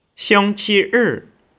(Click on any Chinese character to hear it pronounced.